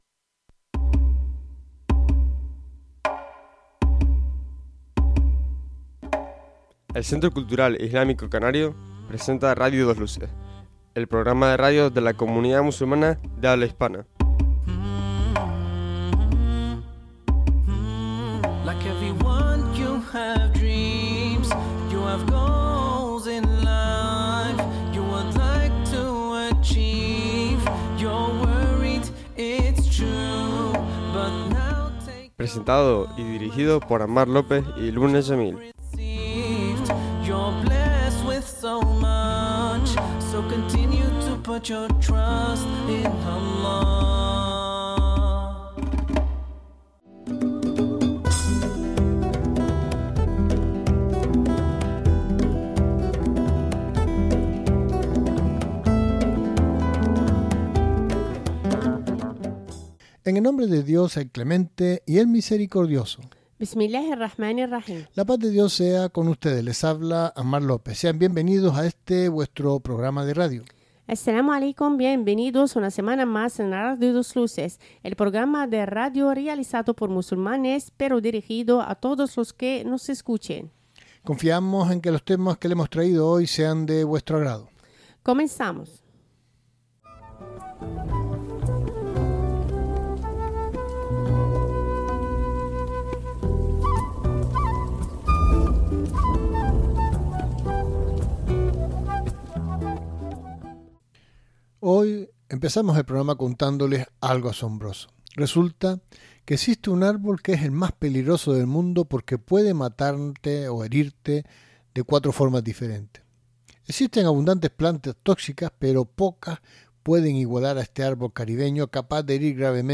El programa completo en Radio 2 Luces. Con todas las secciones de actualidad, debate, entrevistas, música,...